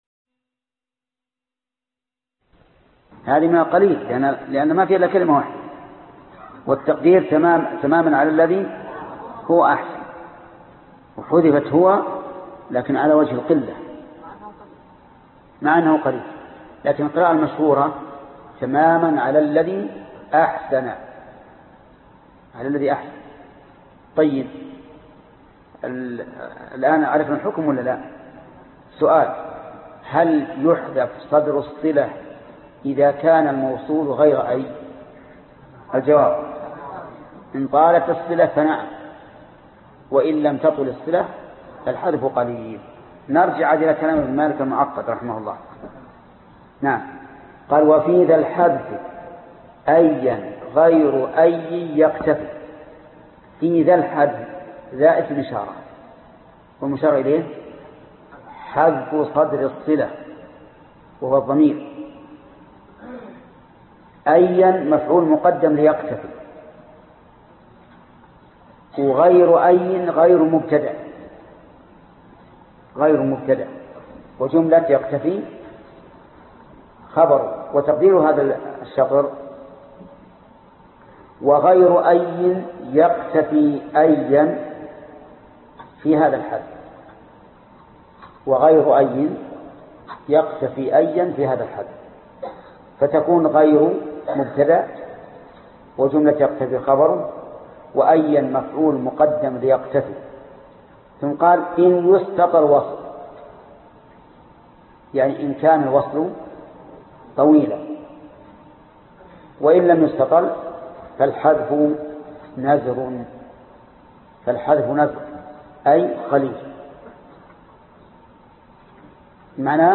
الدرس 75 ( شرح الفية بن مالك ) - فضيلة الشيخ محمد بن صالح العثيمين رحمه الله